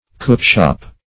Cookshop \Cook`shop\, n. An eating house.
cookshop.mp3